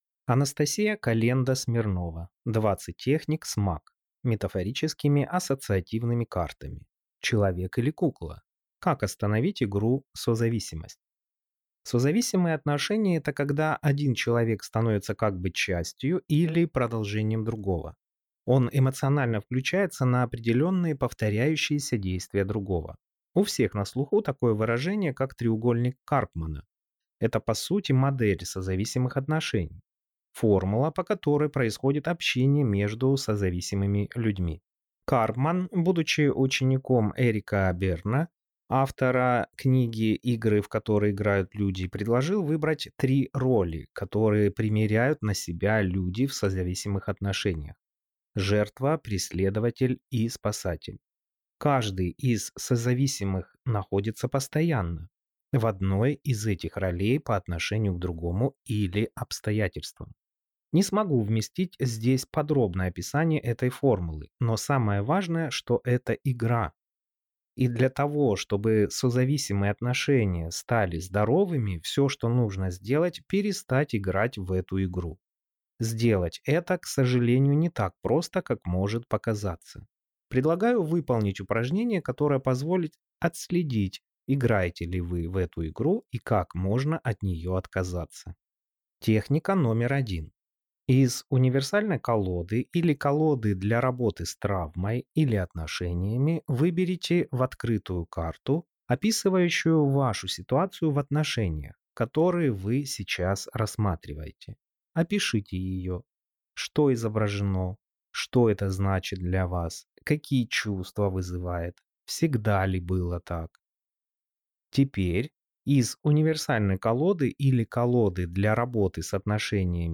Aудиокнига 20 техник с МАК (метафорическими ассоциативными картами)